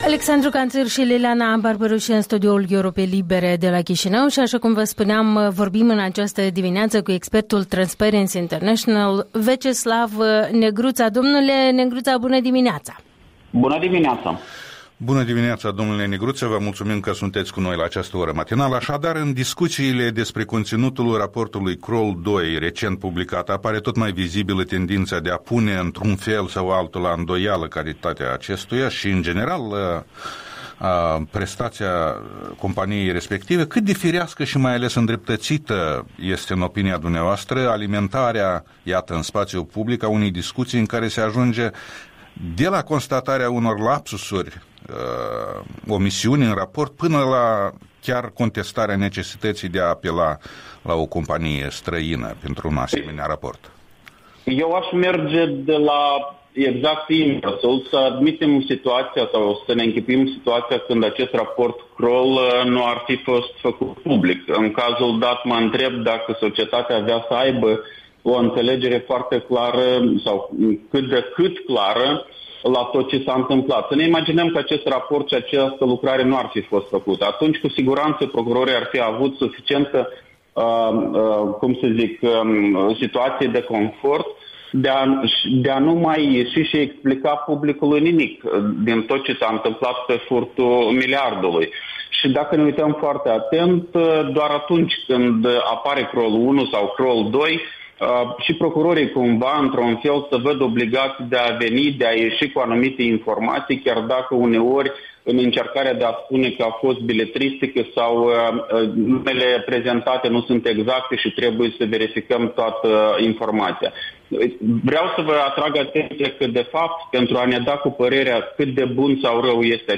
Un interviu cu expertul Transparency International, fost ministru al finanțelor.